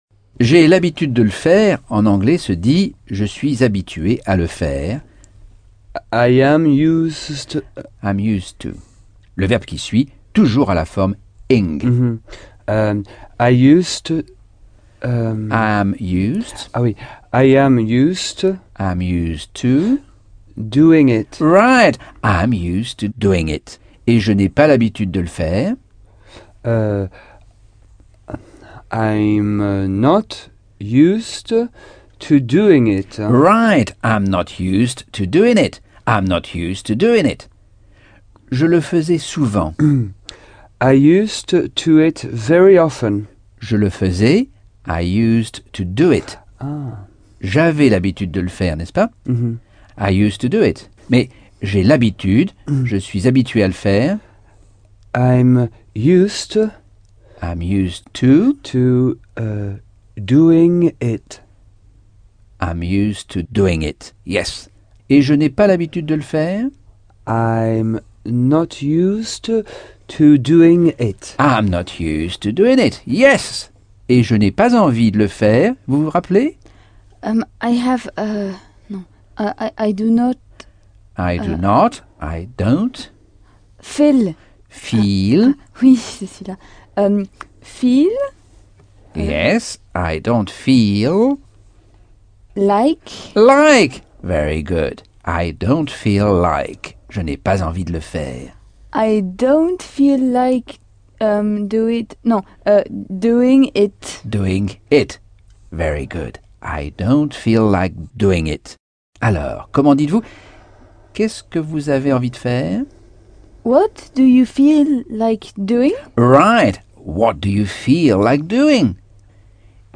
Leçon 9 - Cours audio Anglais par Michel Thomas - Chapitre 9